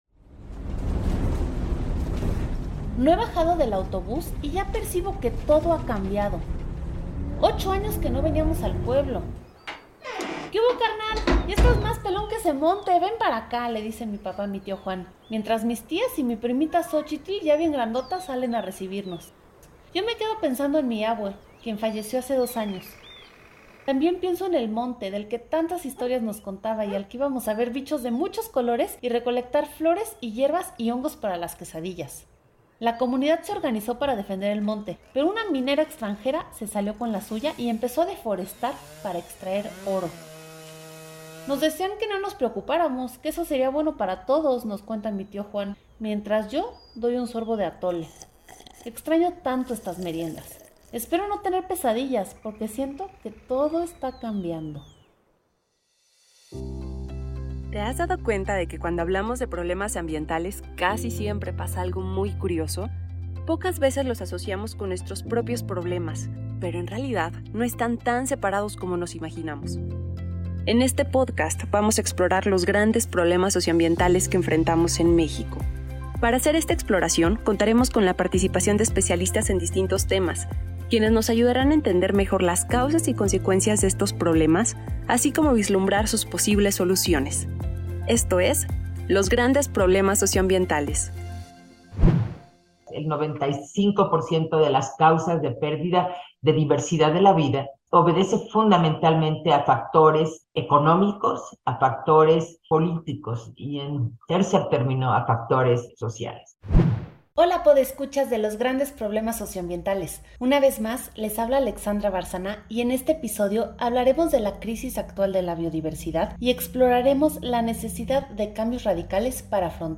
El sistema actual es insostenible y es el que ha creado las crisis que estamos viviendo ahora. En este seminario, nuestros invitados abordan cómo y por qué debemos de empezar a pensar en transformaciones radicales de la manera en la que funciona nuestra sociedad para poder así construir futuros más esperanzadores para humanos y no-humanos.